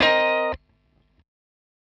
Cm7_9b.wav